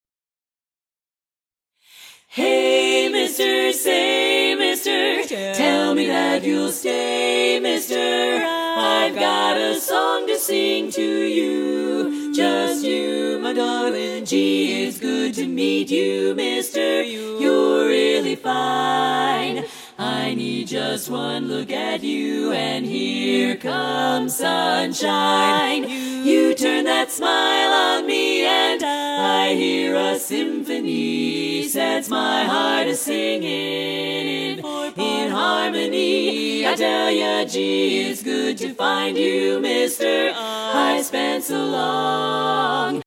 barbershop contest swing song